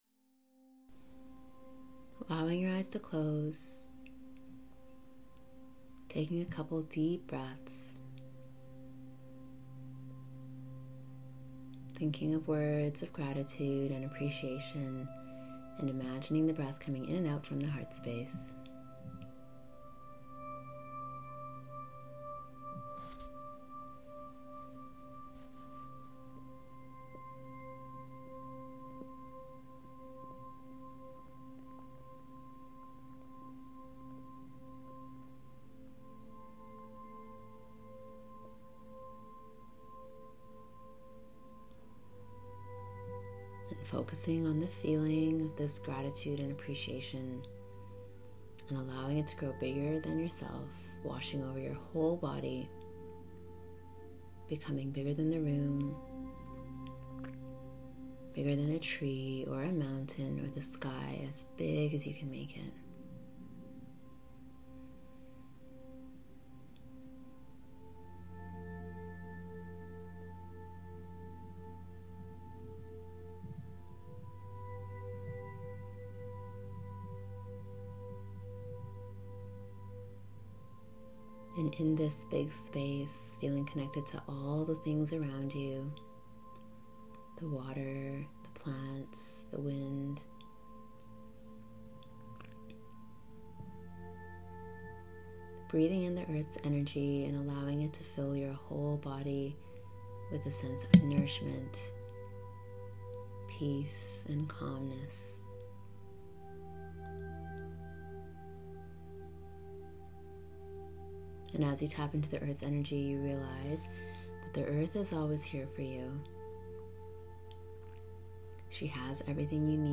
Meditation: Shame and worthiness